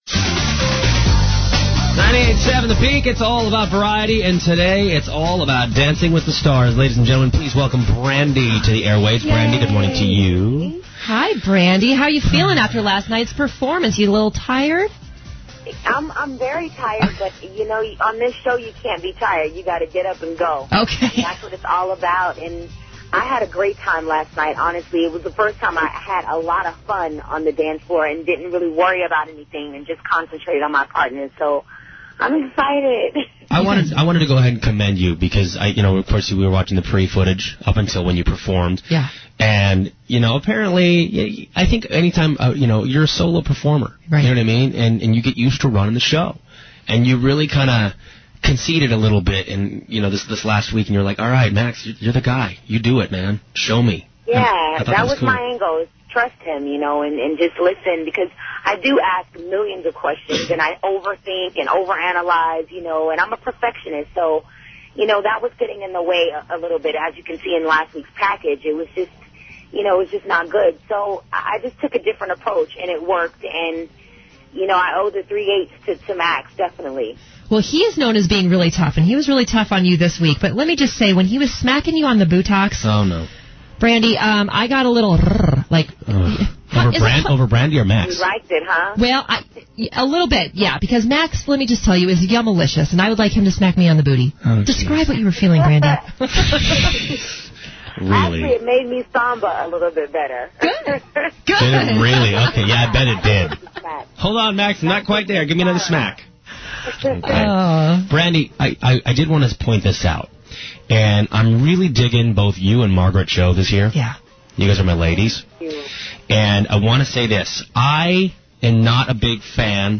Brandy DWTS Interview